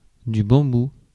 Ääntäminen
IPA : /bæmˈbu/